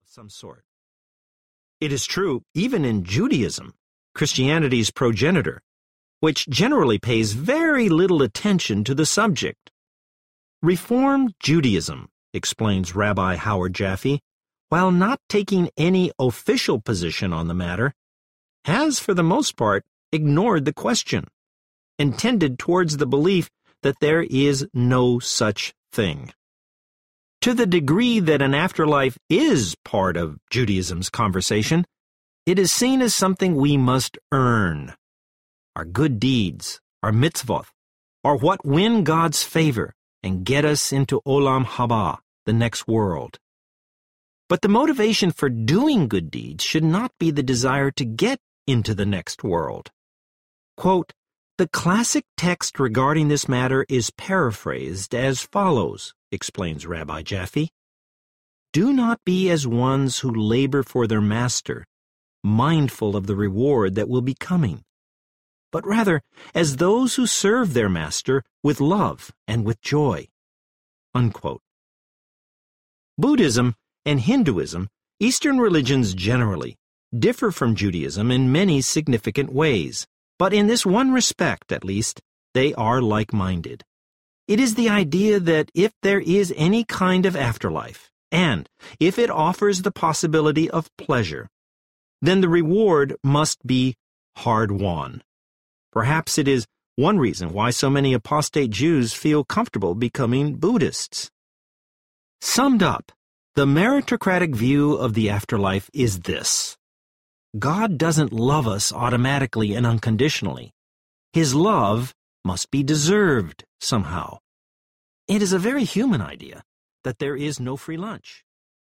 Amazing Truths Audiobook